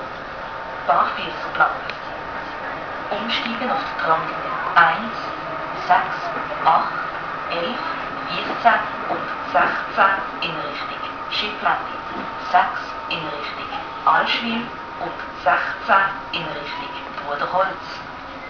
Haltestellenansagen